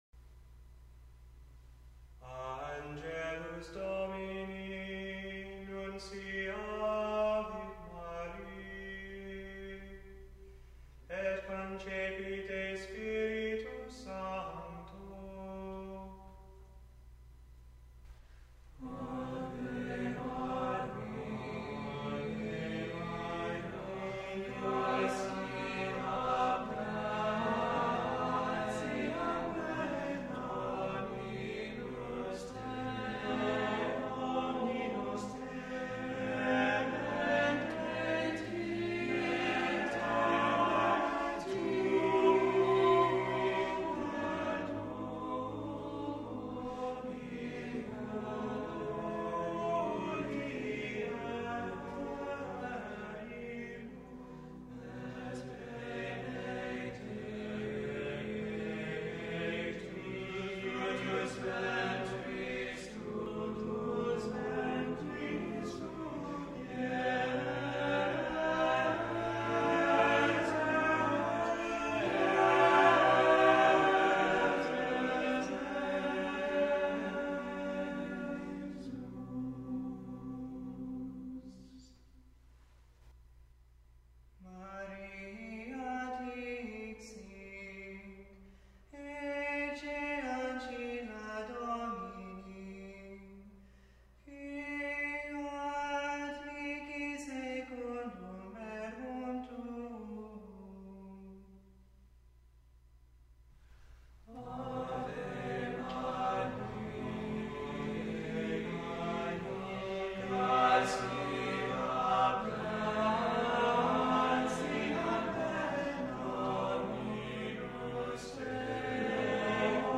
hymns and selections of Gregorian chant